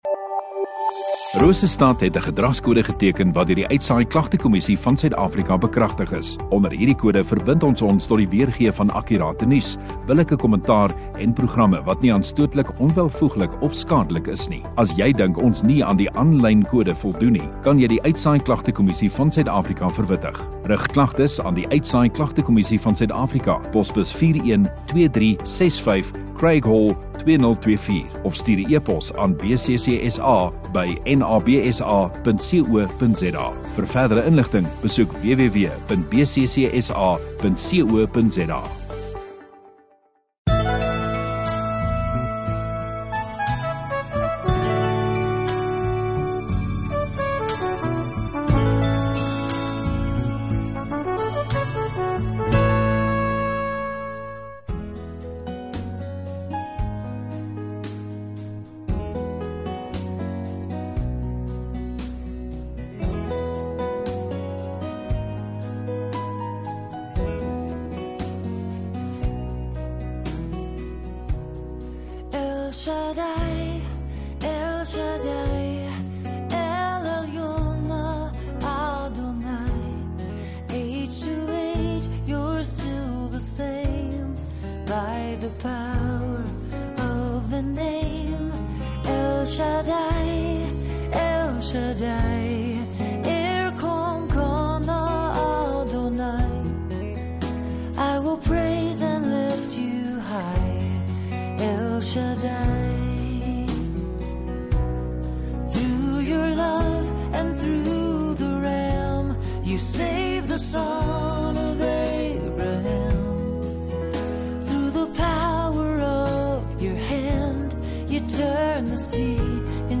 2 Aug Saterdag Oggenddiens